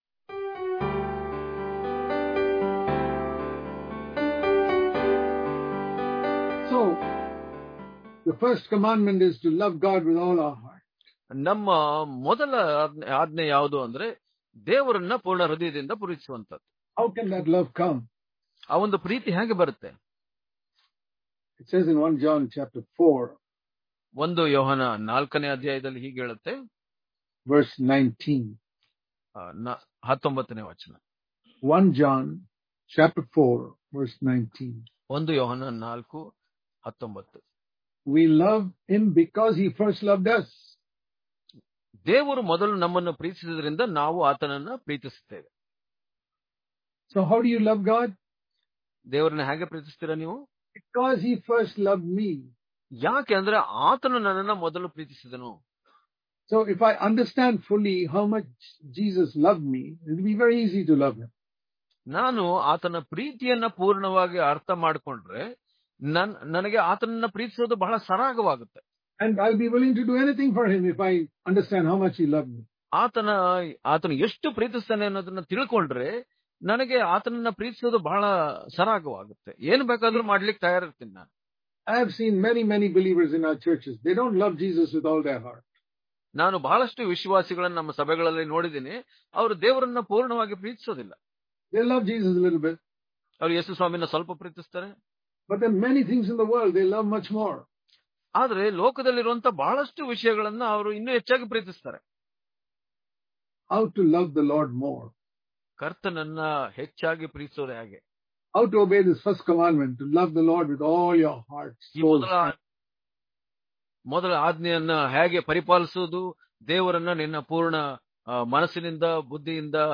Daily Devotions